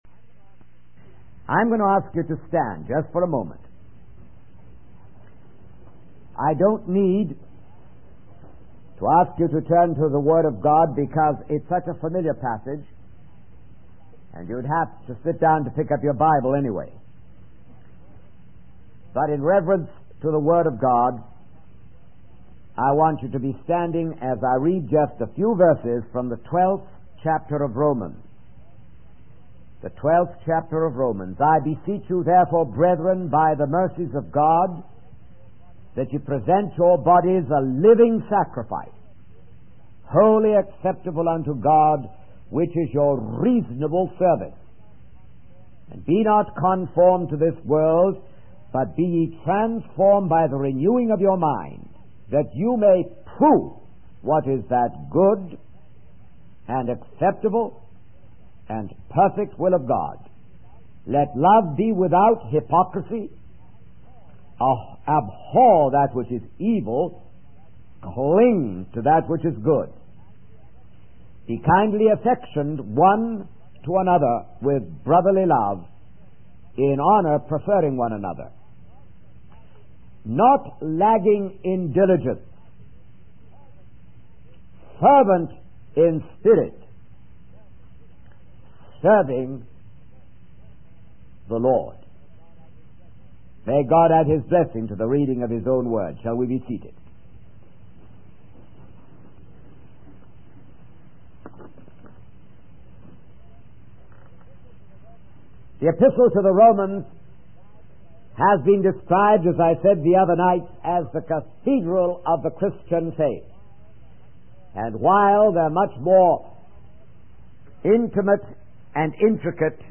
In this sermon, the speaker emphasizes the importance of faithful service to God.